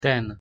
pronunciation_sk_ten.mp3